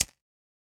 Perc (9).wav